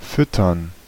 Ääntäminen
Tuntematon aksentti: IPA: [ˈvuːrə(n)]